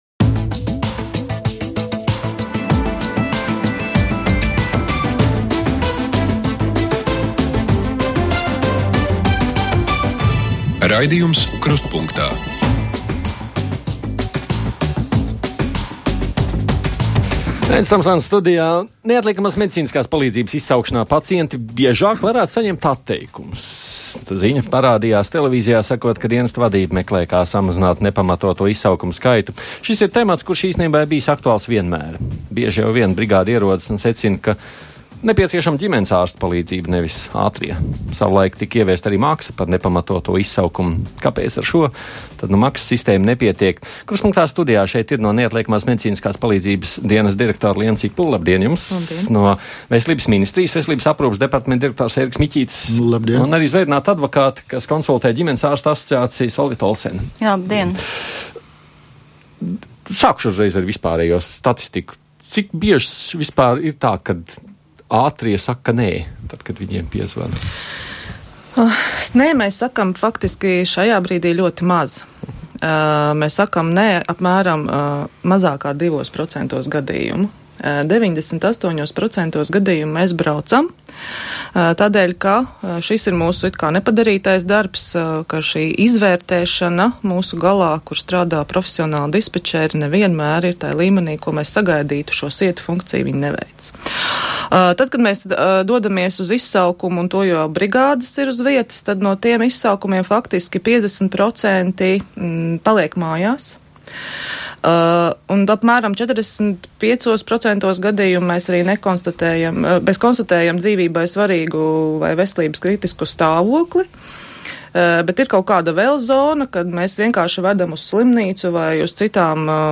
Krustpunktā diskusija par Neatliekamās medicīniskās palīdzības dienesta darbu. Par to, kā izvairīties no nepamatotiem izsaukumiem - diskusija raidījumā..